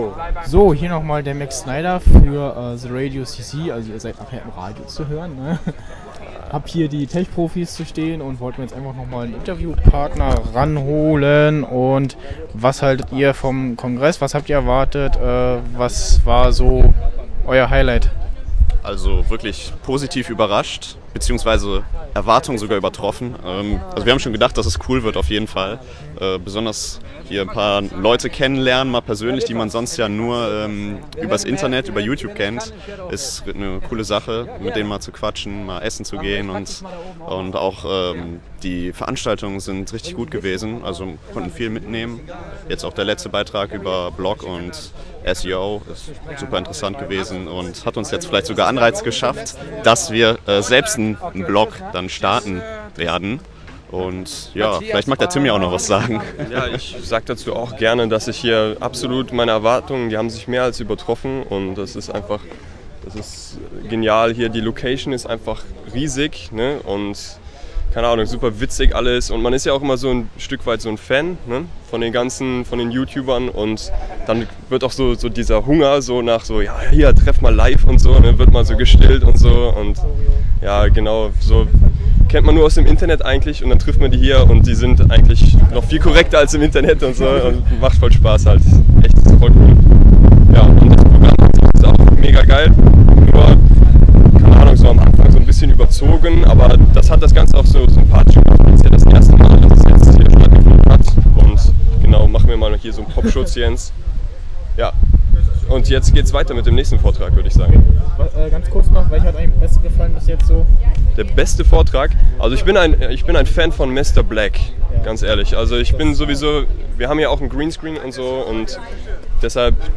Interview mit den TechProfis auf dem YouTube User Congress